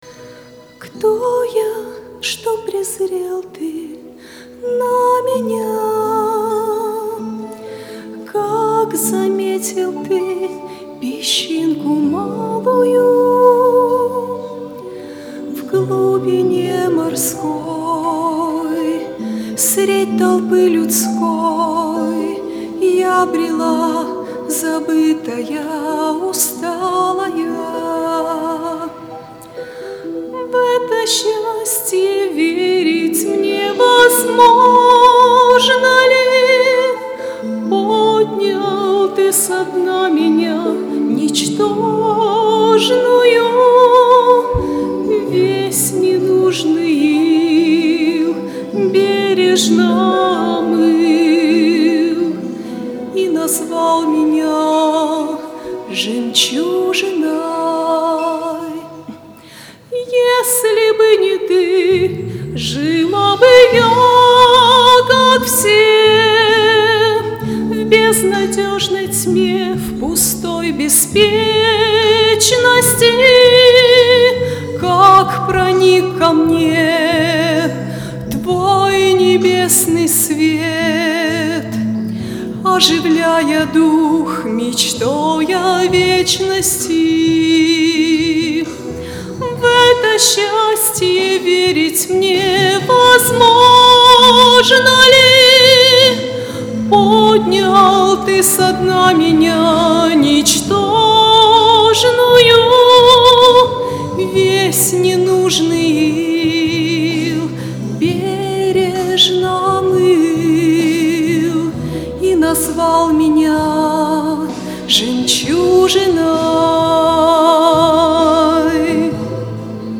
on 2014-07-08 - Фестиваль христианской музыки и песни